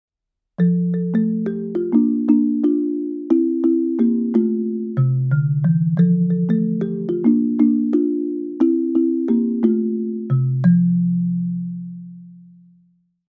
Studio49 BXG 2000 Bass Xylophon
harmonische Obertonstimmung
Mehrfach-Resonanzkammern mit Klanglippen für volumenreichen, unverwechselbaren Klang
Klangbeispiel mit einem Schlägel...